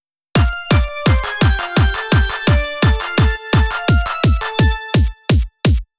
「パン、パン、パン、パン、」という感じですね。
このリズムでバスドラム（キック）を鳴らしたデモがこちらです。